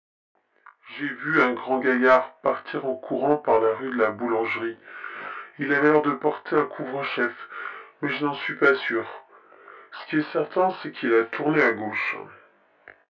Un témoin a des révélations importantes à vous apporter.